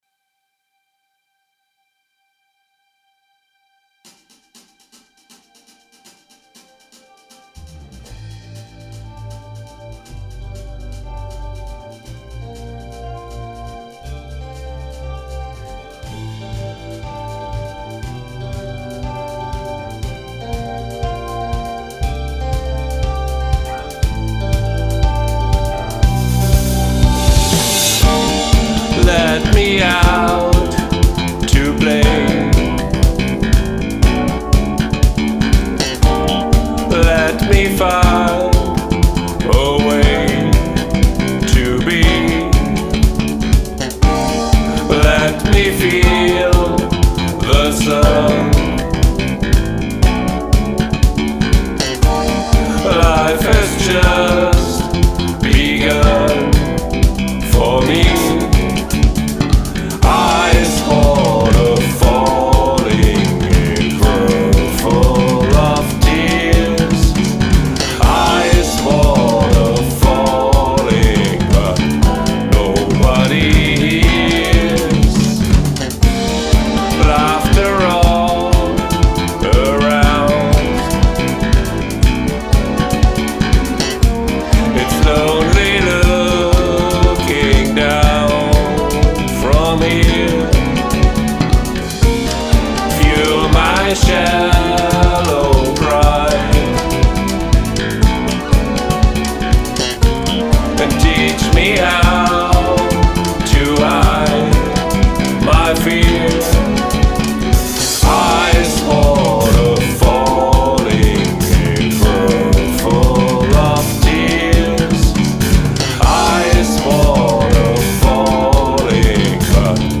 Bass Guitar, Electric Guitar, add.Keys&Drums, Vocals